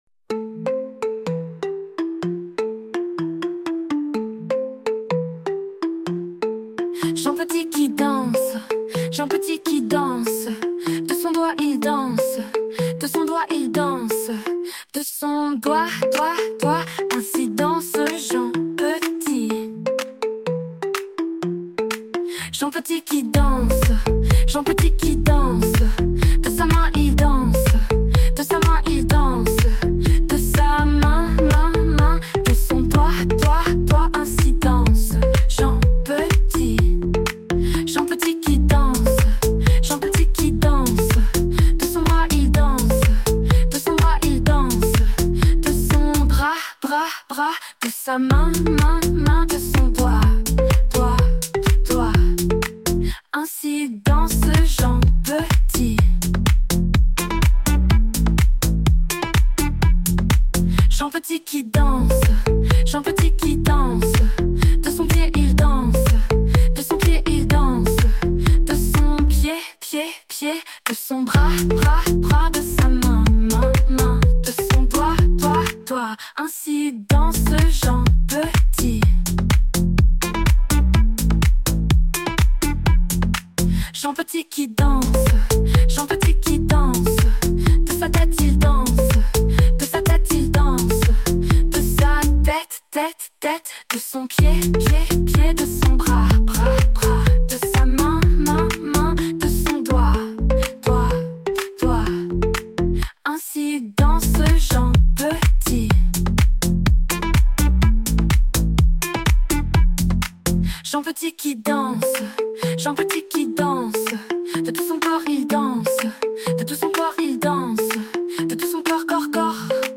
Chanson pour enfants / Comptine éducative
comptine avec gestes
chanson de danse